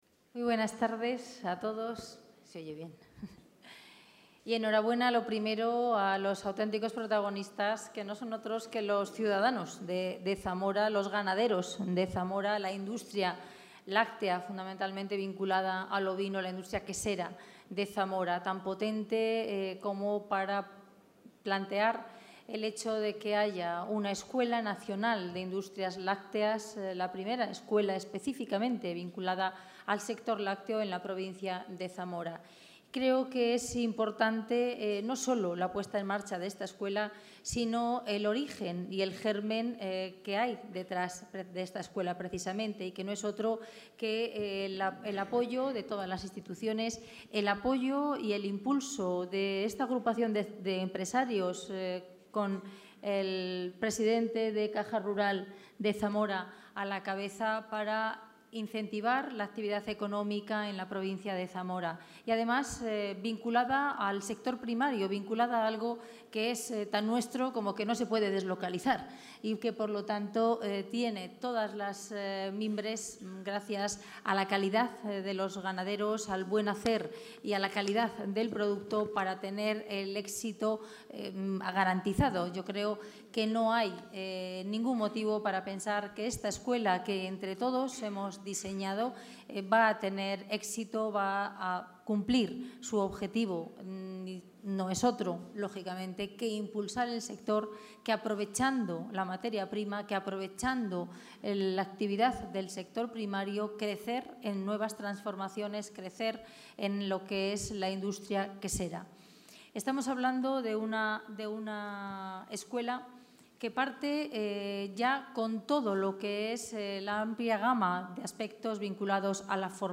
La consejera de Agricultura y Ganadería de la Junta de Castilla y León, Milagros Marcos, ha inaugurado hoy la Feria Ovinnova,...
Intervención de la consejera de Agricultura y Ganadería presentación de la Escuela Nacional de Industrias Lácteas.